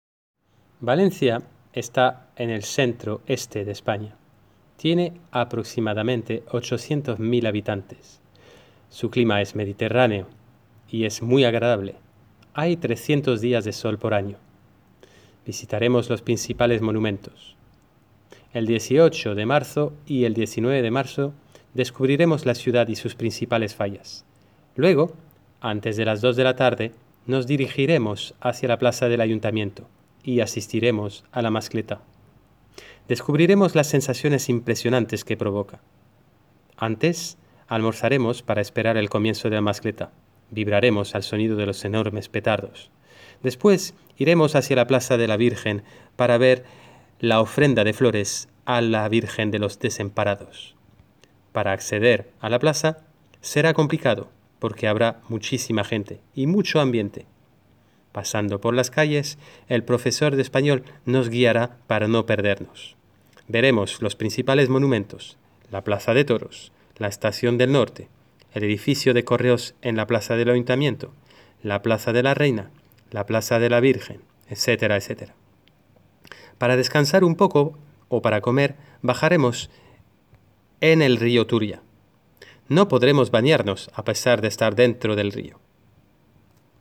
Lecturas ejemplos
Lectura-fallas.m4a